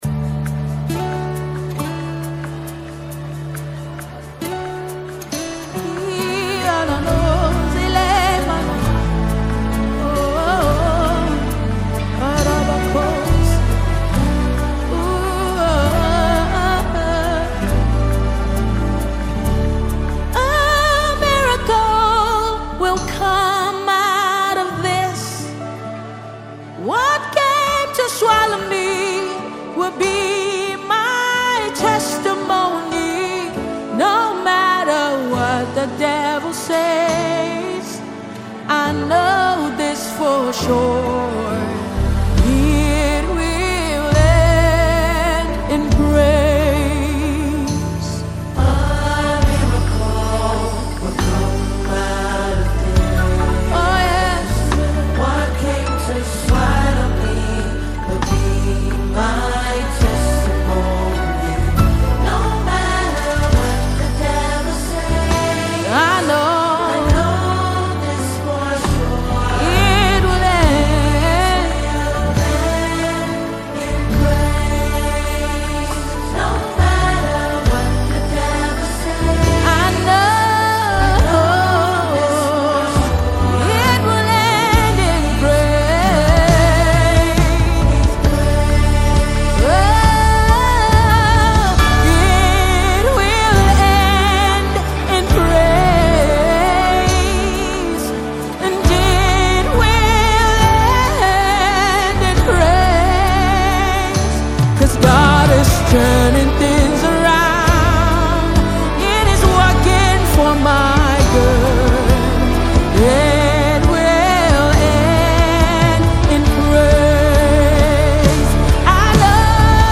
Nigeria Gospel Music